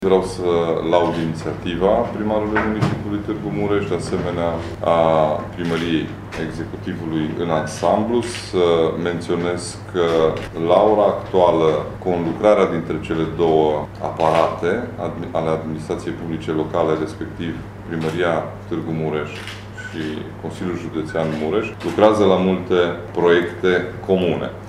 Președintele Consiliului Județean Mureș, Ciprian Dobre a apreciat inițiativa Primăriei și a arătat că pe viitor vor exista mai multe proiecte comune ale celor două instituții.